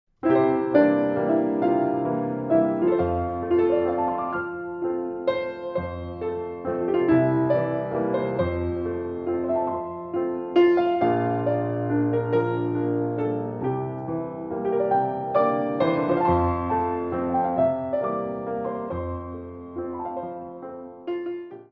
Ballet Music for All Level Classes
Solo Piano
Moderate Waltzes